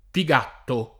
[ pi g# tto ]